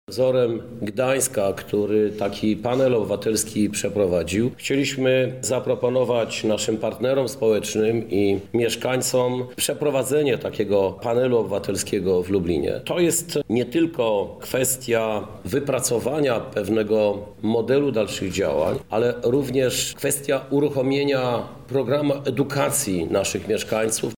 Chcemy jednak także poznać zdanie lublinian – mówi Prezydent Miasta Krzysztof Żuk i tłumaczy, skąd wziął się pomysł na konsultacje z mieszkańcami: